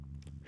• demonic techno voice "hear it".wav
Changing the pitch and transient for a studio recorded voice (recorded with Steinberg ST66), to sound demonic/robotic.